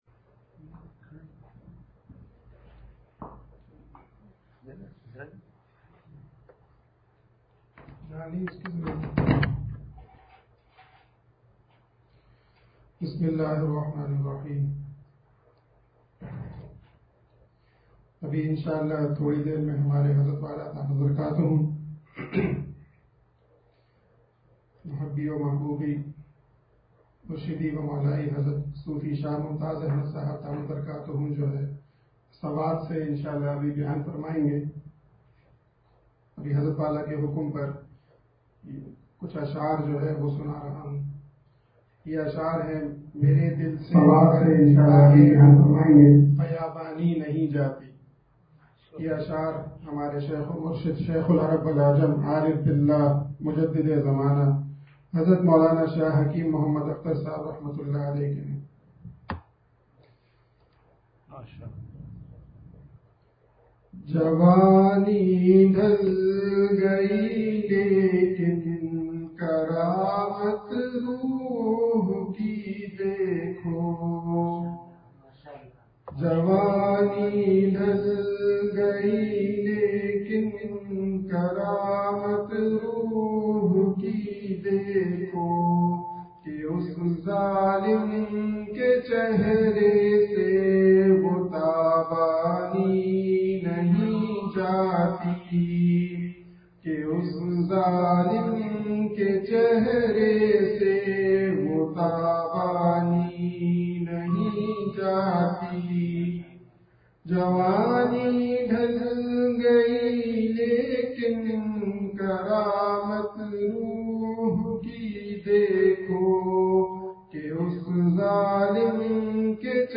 حضرت والا دامت بر کاتہم کا بیان سوات سے